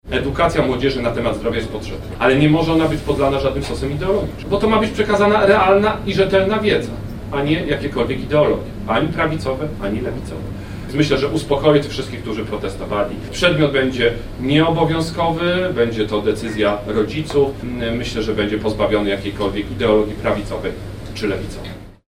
Kosiniak-Kamysz został zapytany podczas konferencji prasowej Polskiego Stronnictwa Ludowego w Szczecinie o protesty przeciwko wprowadzeniu obowiązkowego przedmiotu edukacja zdrowotna w szkołach, jakie odbyły się w sobotę w tym mieście, a w niedzielę – w Krakowie i Radomiu.